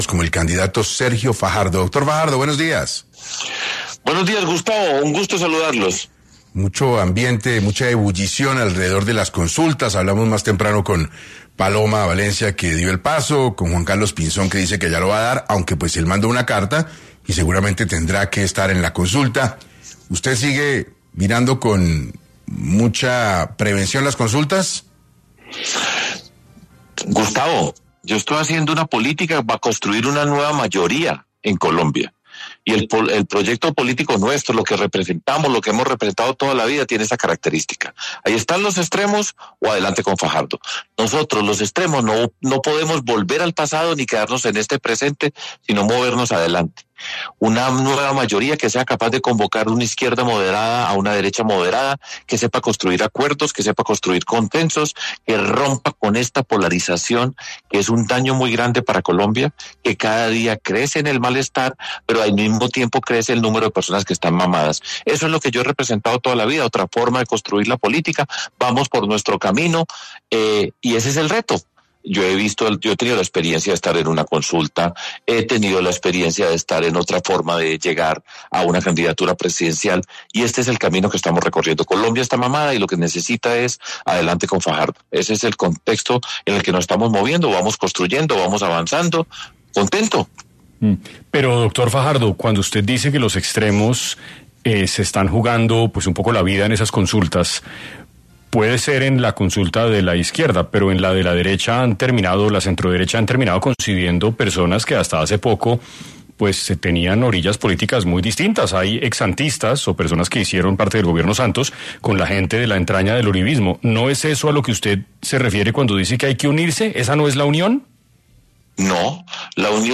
En 6AM de Caracol Radio estuvo el precandidato Sergio Fajardo, quien reveló las razones por la que no irá a una consulta y con quiénes no haría alianzas políticas
Durante la entrevista, el candidato insistió en que su propuesta busca convocar a ciudadanos que votaron en blanco en 2018 y 2022, así como a quienes no se sienten representados ni por el uribismo ni por el petrismo.